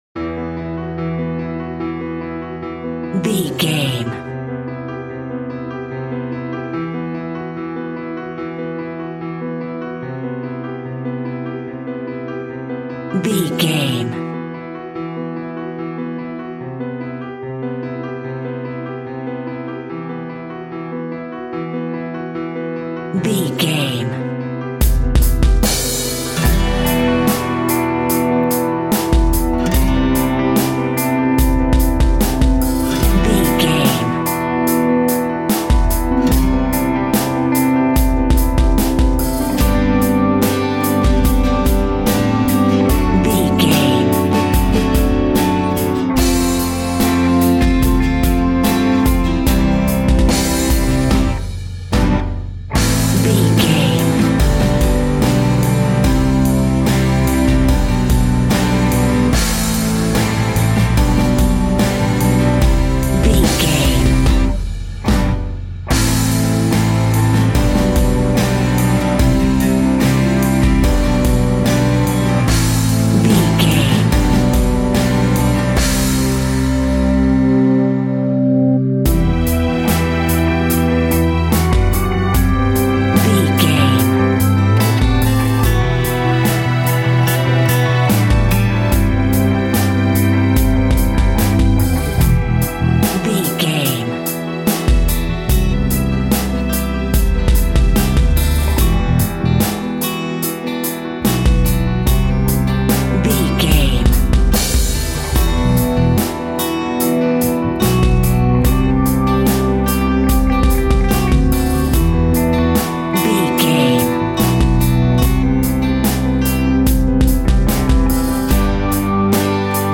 Aeolian/Minor
distortion
Instrumental rock
acoustic guitar
drums
piano
electric guitar